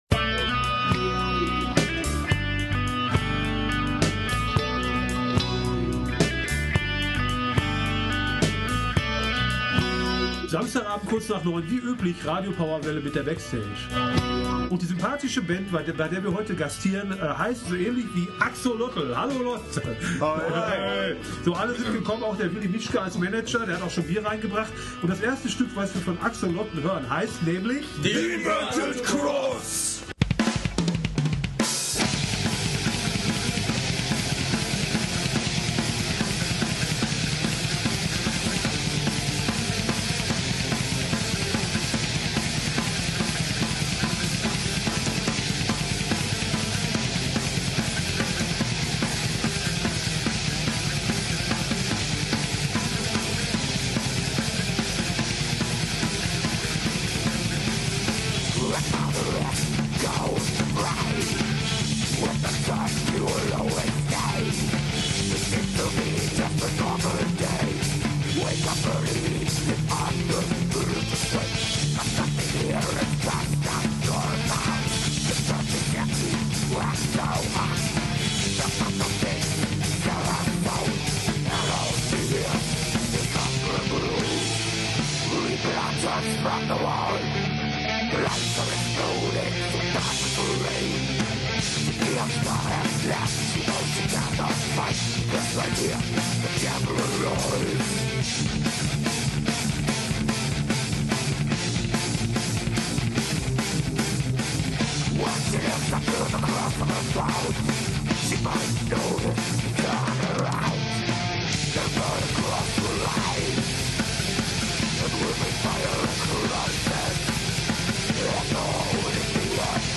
Aus rechtlichen Gründen sind die letzten beiden Musikstücke ausgeblendet.